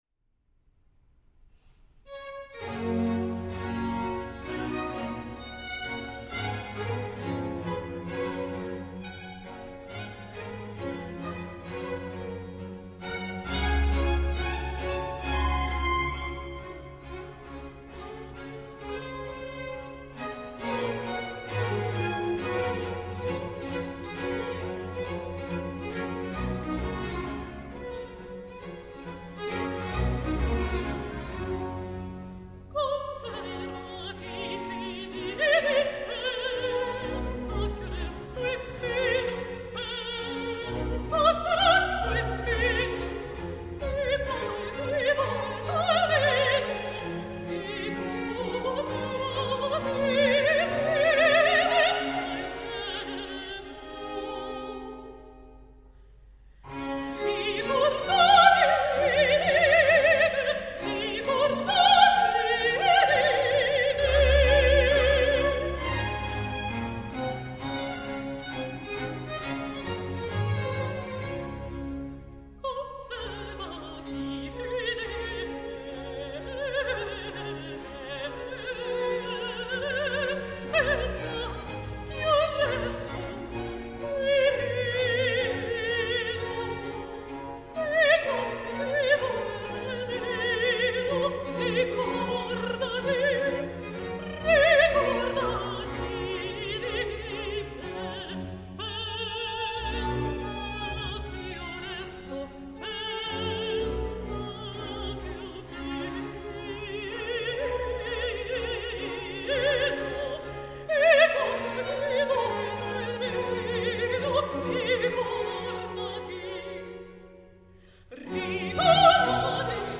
Élő felvétel a 2019. június 7-i koncertről a Bajor Nemzeti Múzeumból.